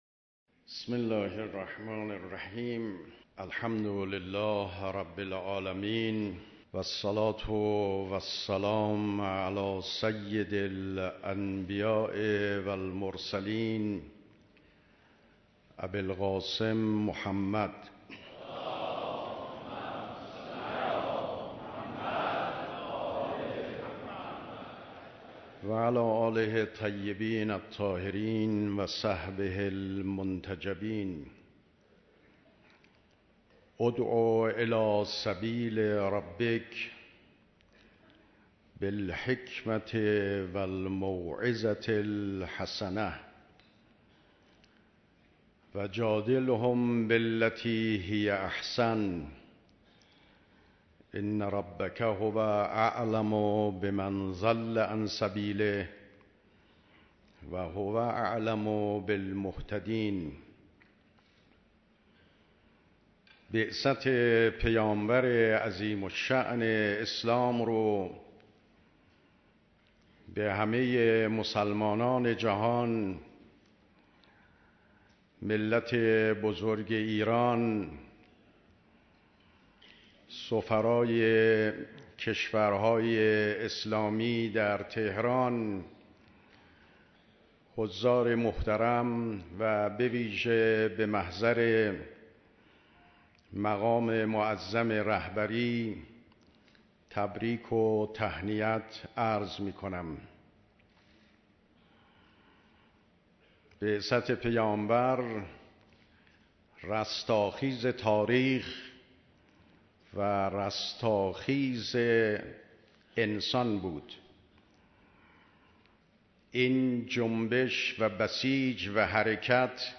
دیدار مسئولان نظام، سفیران کشورهای اسلامی و قشرهای مختلف مردم به‌مناسبت عید مبعث
سخنرانی ریاست محترم جمهور جناب آقای روحانی